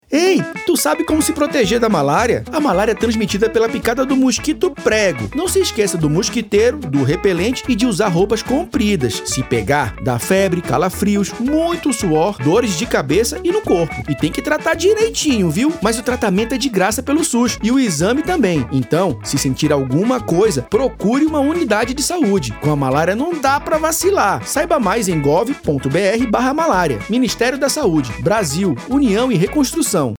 Áudio - Spot 30s - Malária - 1.15mb .mp3 — Ministério da Saúde